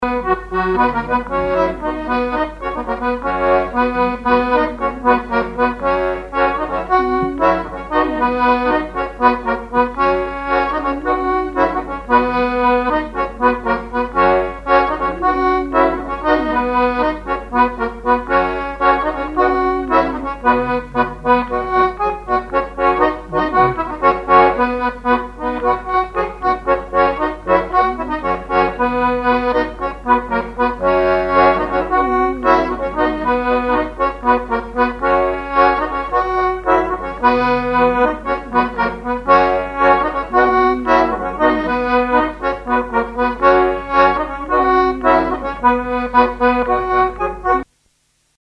Couplets à danser
branle : courante, maraîchine
Répertoire sur accordéon diatonique
Pièce musicale inédite